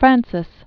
(frănsĭs) 1494-1547.